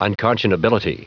Prononciation du mot unconscionability en anglais (fichier audio)
Prononciation du mot : unconscionability